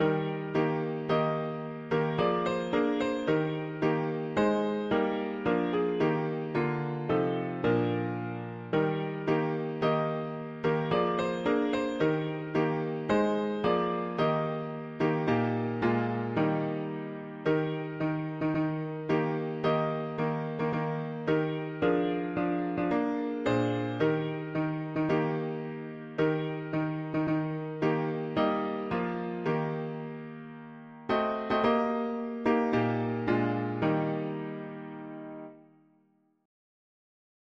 Key: E-flat major
Tags english christian easter 4part